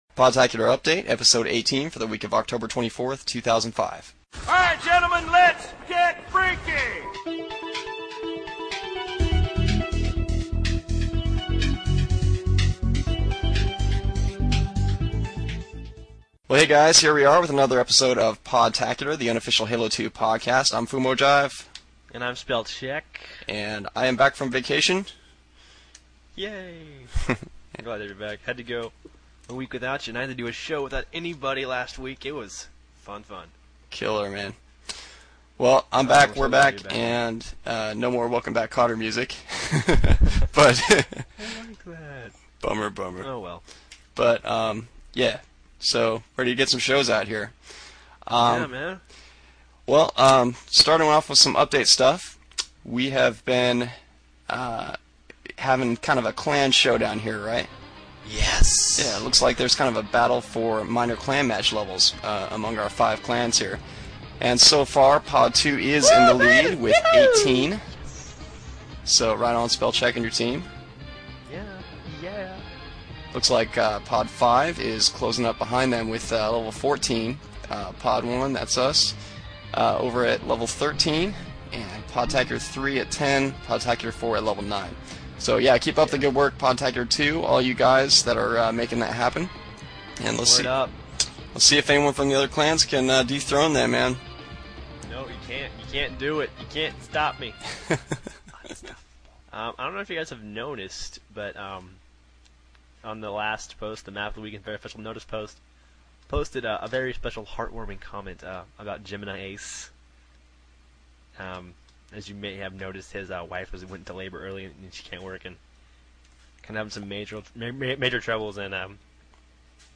Hope you like our new higher quality shows!